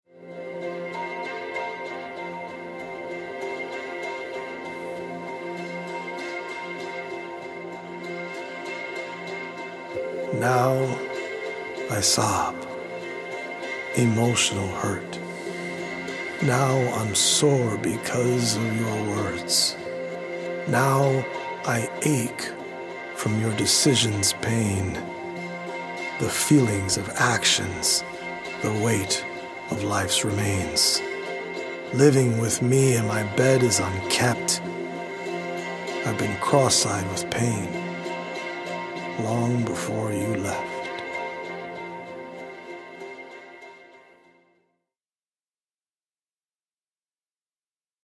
as well as healing Solfeggio frequency music by EDM producer